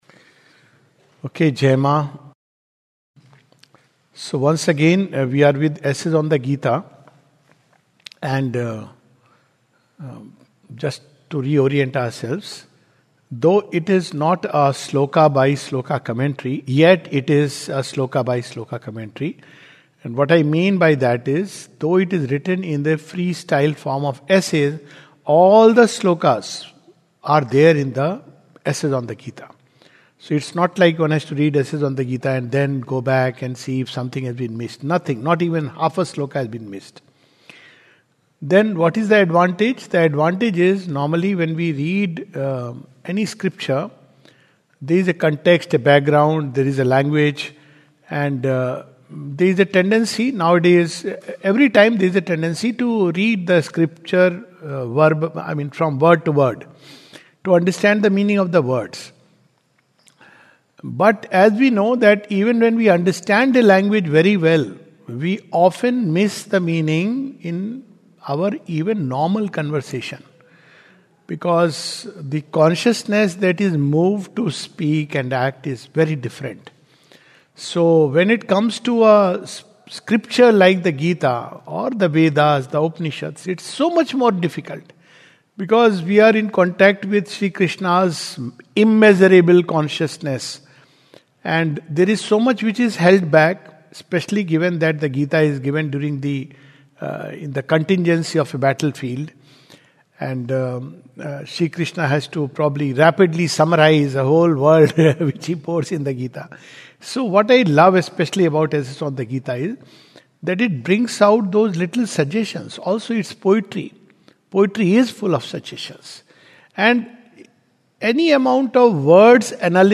This is a summary of Chapter 16 of the Second Series of "Essays on the Gita" by Sri Aurobindo. A talk
2025 at the Savitri Bhavan, Auroville.